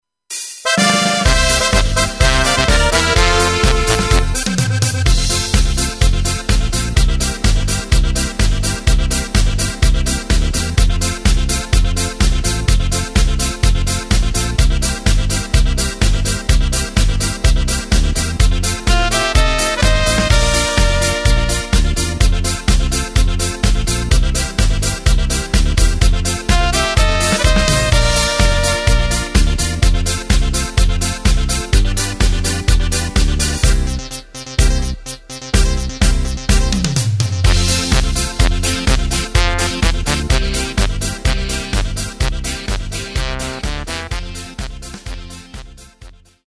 Playback mp3 mit Lyrics